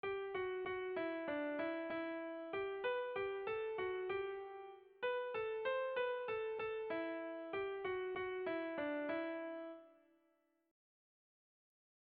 Erromantzea
Lauko txikia (hg) / Bi puntuko txikia (ip)
AB